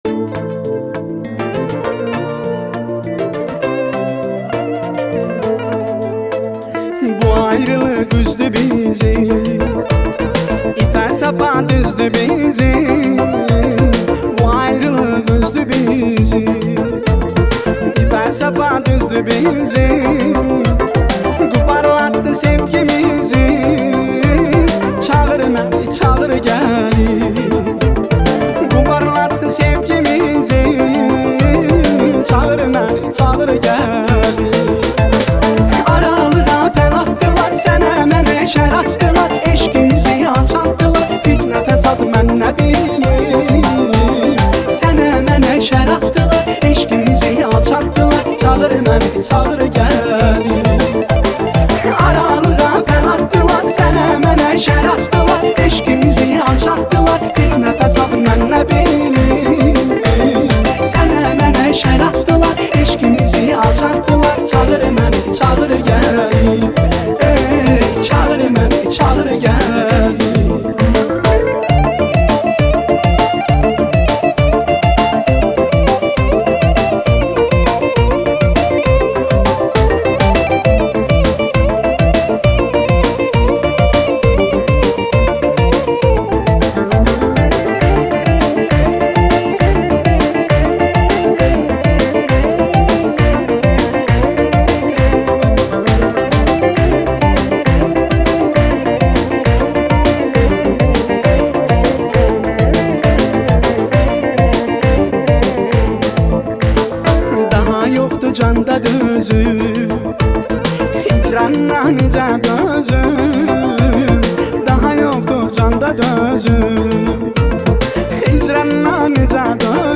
آهنگ آذری